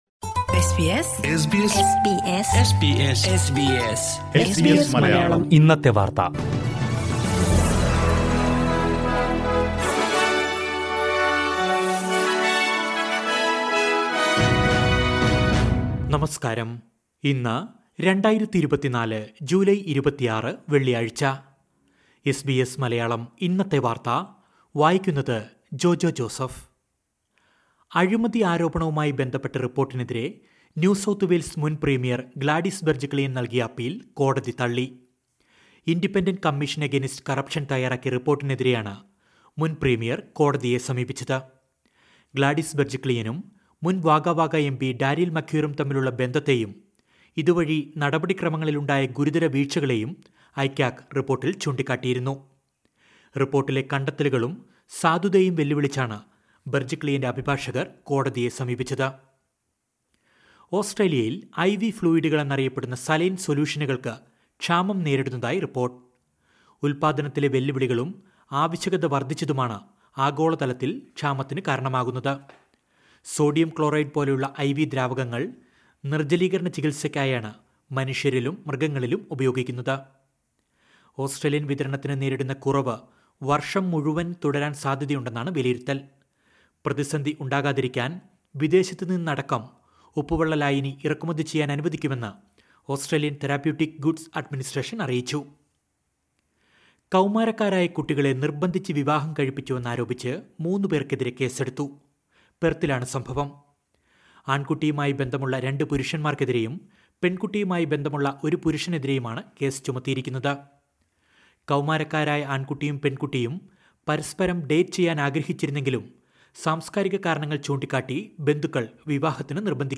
2024 ജൂലൈ 26ലെ ഓസ്‌ട്രേലിയയിലെ ഏറ്റവും പ്രധാന വാര്‍ത്തകള്‍ കേള്‍ക്കാം...